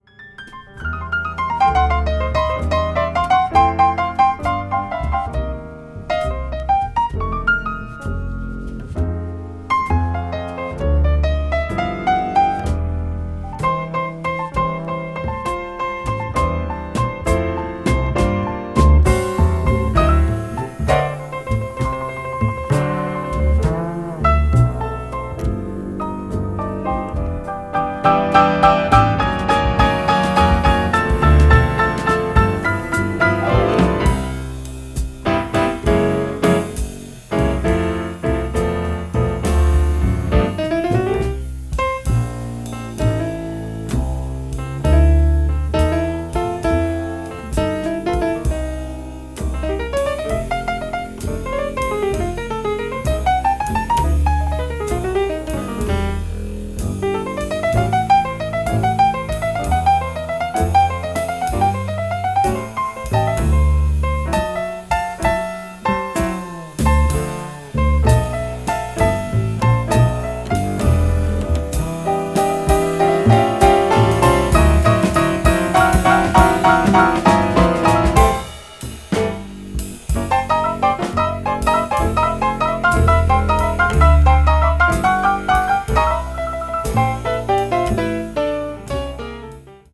contemporary jazz   jazz standard   modal jazz   modern jazz